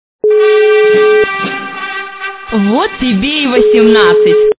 Вот тебе и 18 - говорит нежный женский голос Звук Звуки Вот тебе и 18 - говорит нежный женский голос
» Звуки » Люди й фрази » Вот тебе и 18 - говорит нежный женский голос
При прослушивании Вот тебе и 18 - говорит нежный женский голос качество понижено и присутствуют гудки.